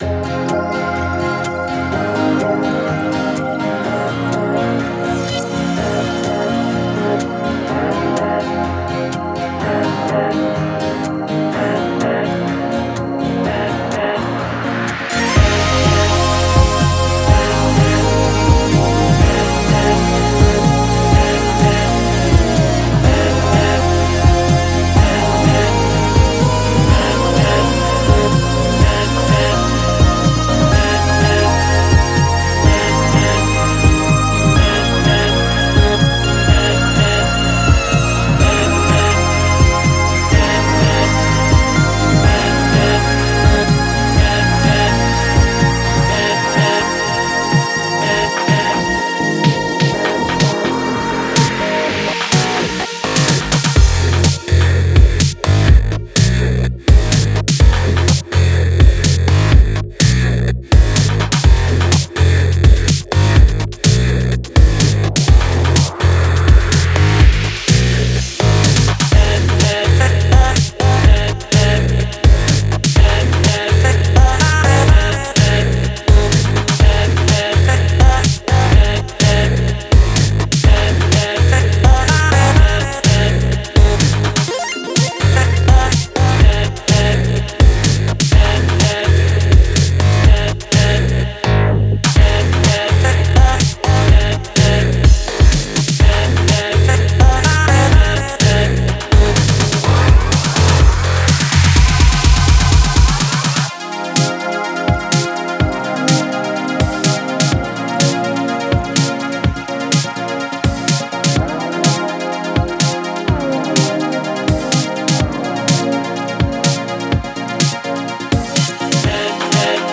Out There (Electronica